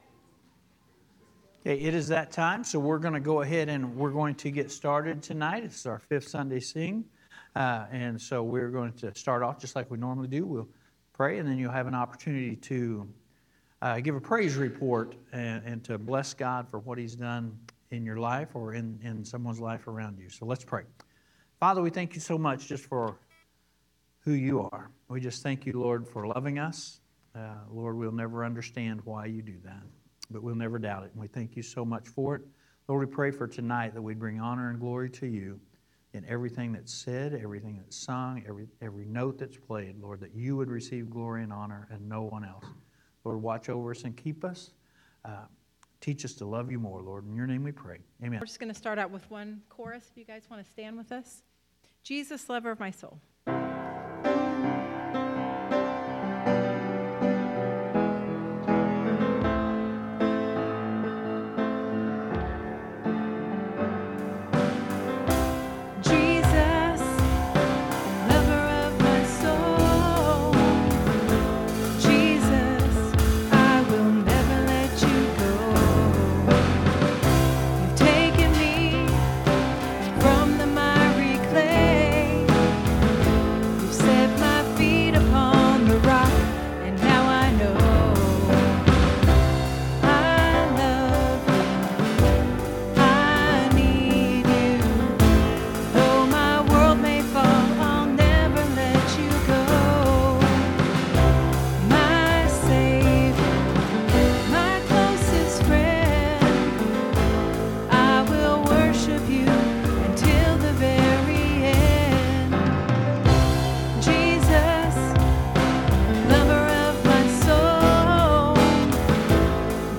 5th Sunday Sing